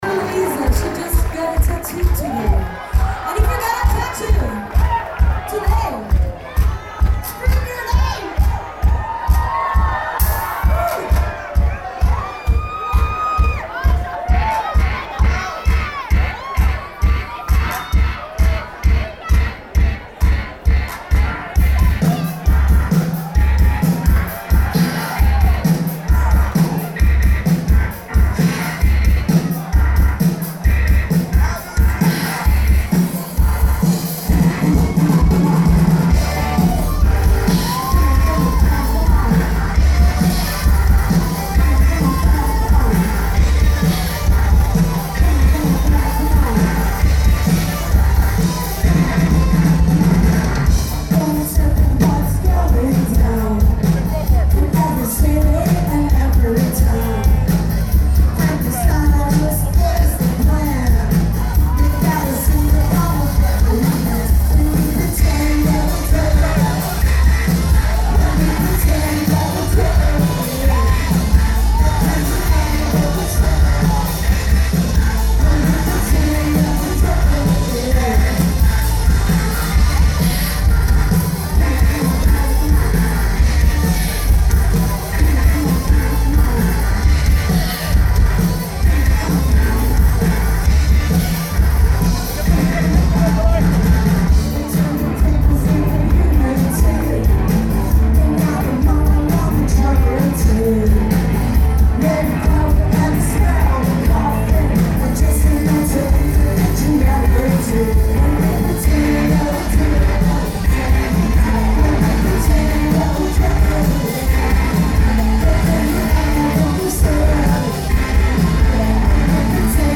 cover
was recorded at Irving Plaza